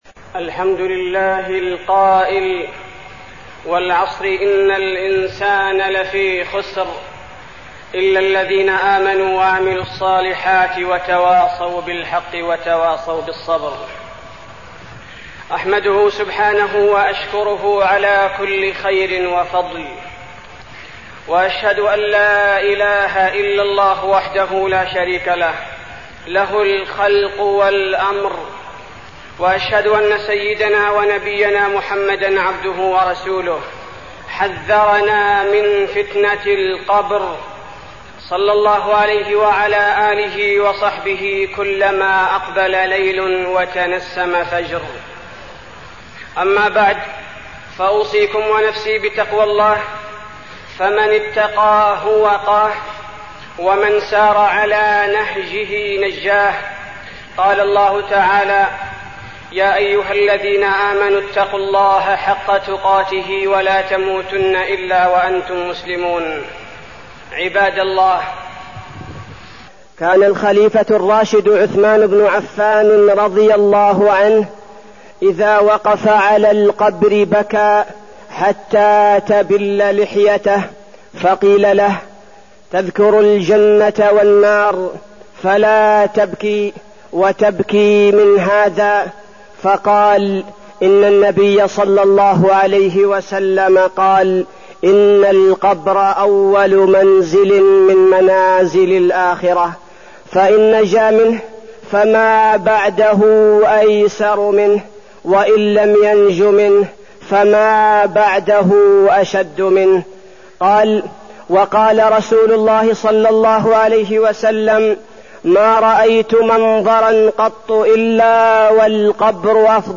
تاريخ النشر ٨ شعبان ١٤١٩ هـ المكان: المسجد النبوي الشيخ: فضيلة الشيخ عبدالباري الثبيتي فضيلة الشيخ عبدالباري الثبيتي عذاب القبر